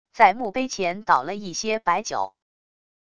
在墓碑前倒了一些白酒wav音频